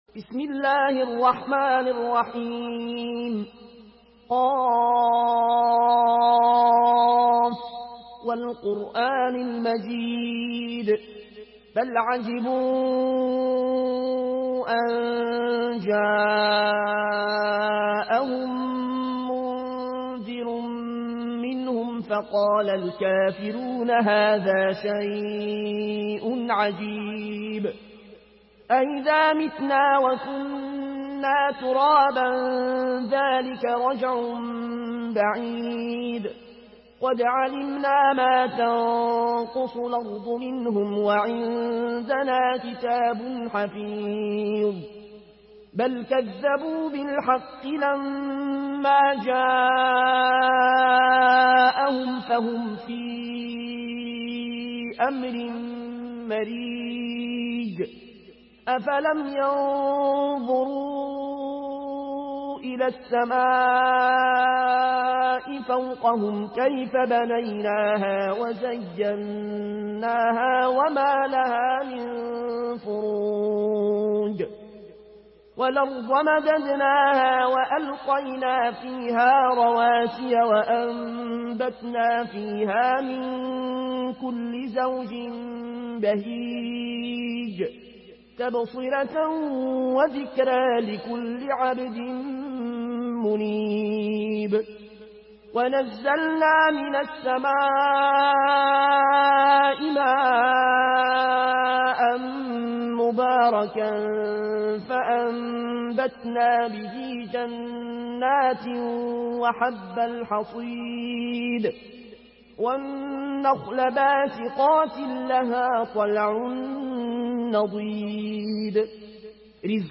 Une récitation touchante et belle des versets coraniques par la narration Warsh An Nafi From Al-Azraq way.
Murattal